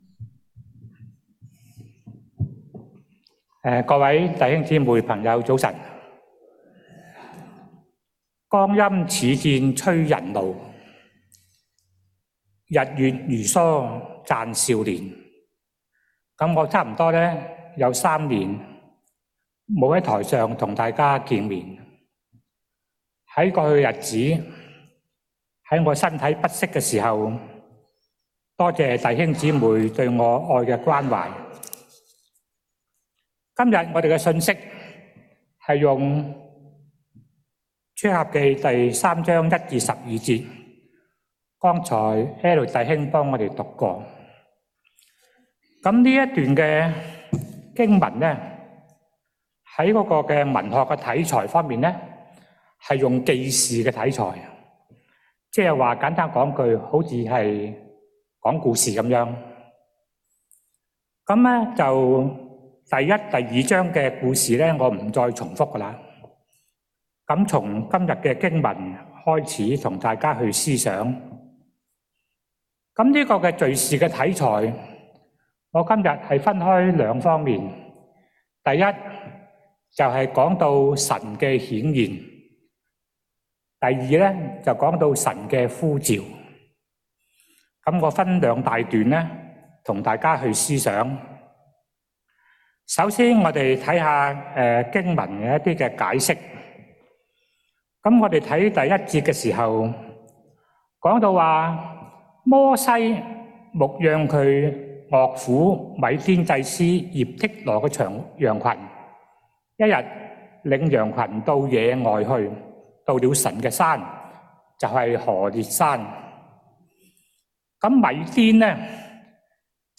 sermon0402Canto.mp3